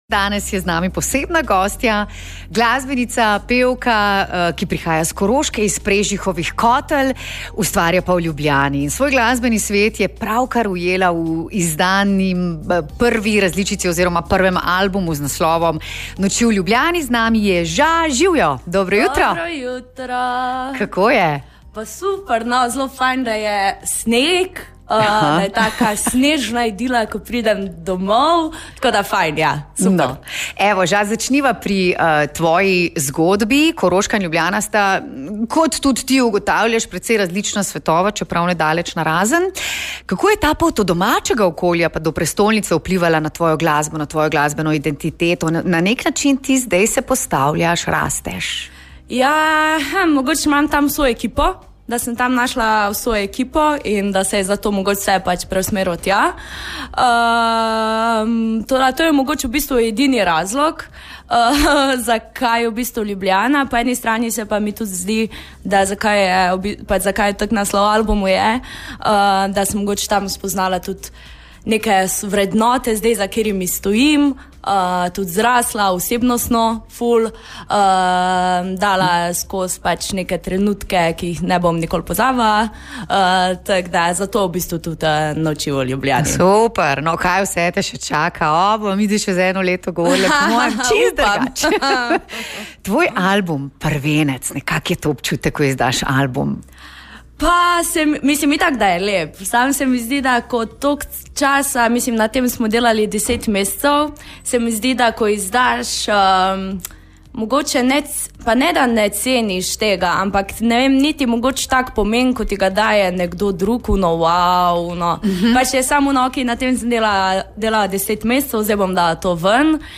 Kaj ji pomeni prvi album in kako se sliši v živo, prisluhnite:
Avtorske pesmi so osebne, čustvene in se mešajo v funki ritmih.